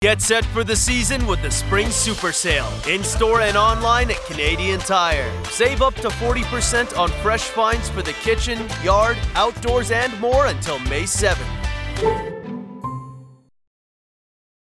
Male
Yng Adult (18-29), Adult (30-50)
My voice is filled with ENERGY and I can do reads for any voice over you might need.
Radio Commercials